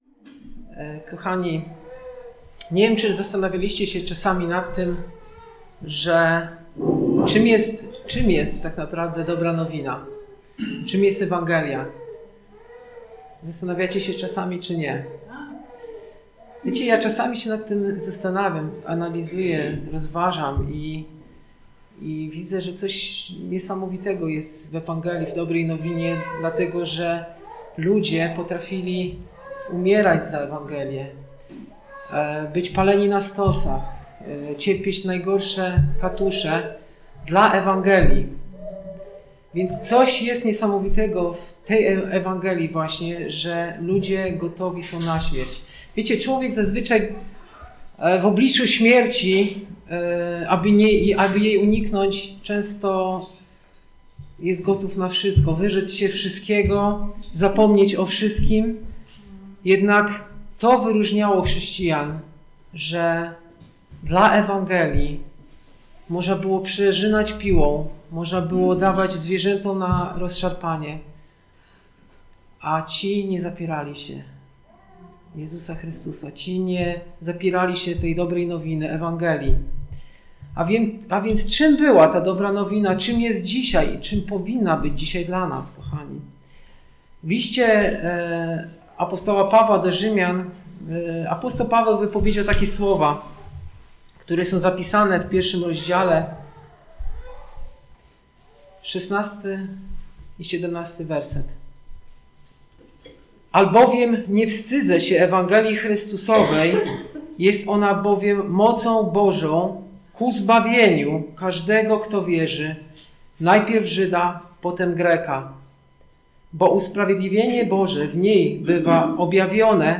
Kazanie
Posłuchaj kazań wygłoszonych w Zborze Słowo Życia w Olsztynie